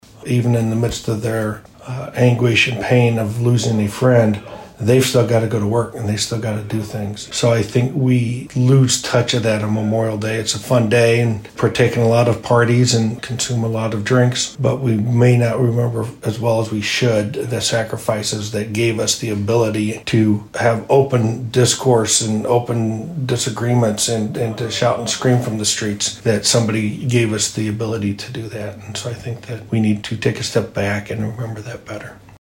Humboldt Police Chief Joel Sanders hopes people remember those who lost their lives while serving, as well as the loved ones who lost someone: